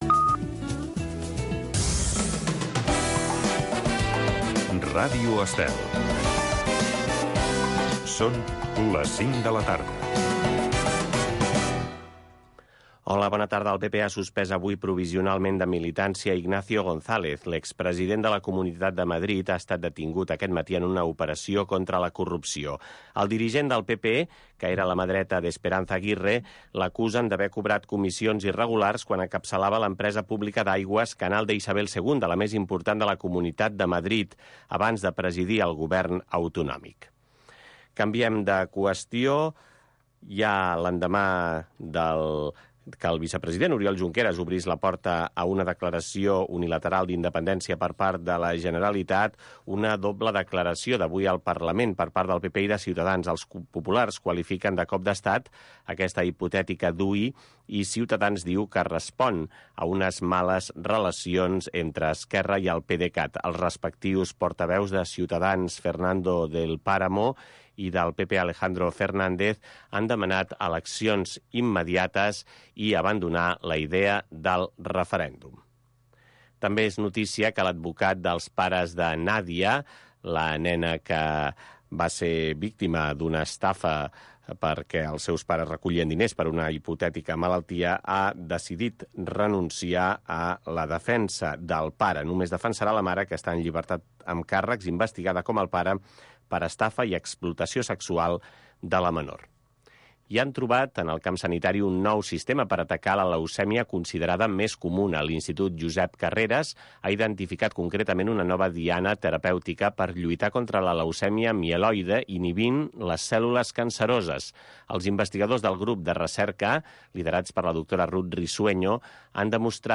Estem amb tu. Magazín cultural de tarda.